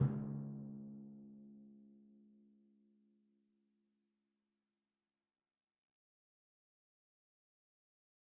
VSCO 2 CE 敲击乐 定音鼓 " 定音鼓 C3 ( 定音鼓3打v3 rr2 Sum)
标签： 单票据 多重采样 MIDI音符-49 打击乐器 定音鼓 MIDI -velocity-85 csharp3 VSCO-2
声道立体声